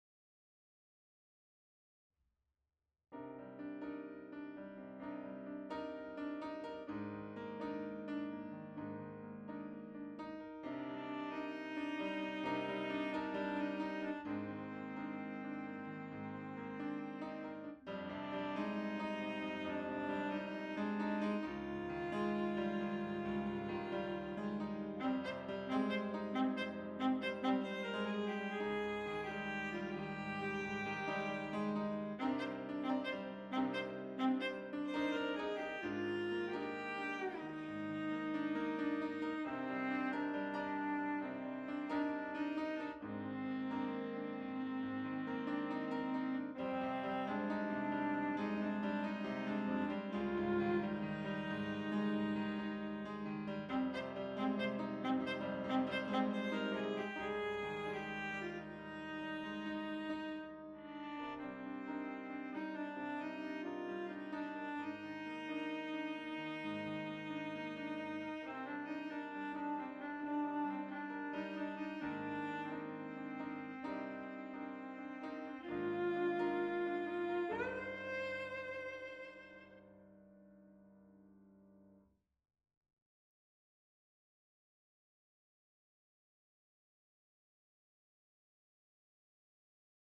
Several years ago, a close friend of mine who had started a music, computer software company, had me record a piano improvisation piece in order to test out some of his new software programs, which he had developed.
The second two include the added melody, with the change from a piano to a flute, and then a change to a viola respectively…
Piano and Viola (Unfortunately, this recording is at a very low volume, but with headphones it can be heard easily.)
piano-and-viola-aif.m4a